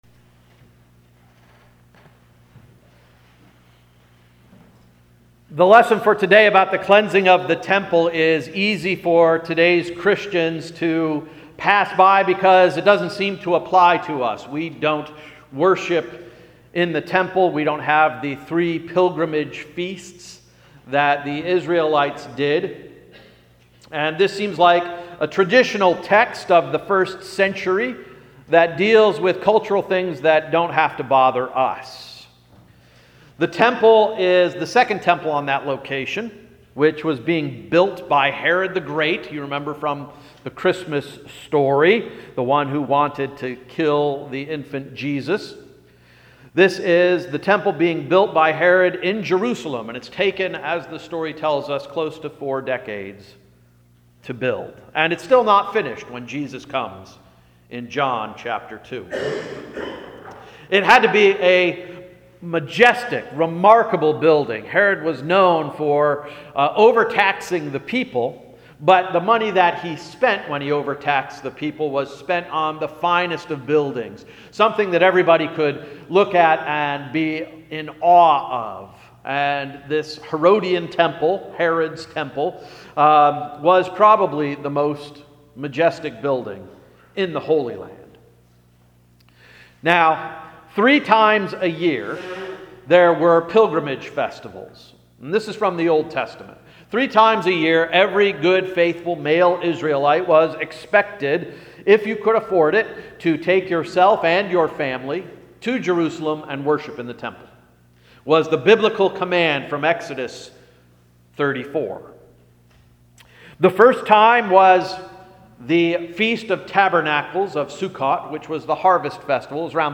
September 3, 2017 Sermon — “Spring Cleaning”